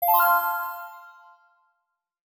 Bell Star 2.wav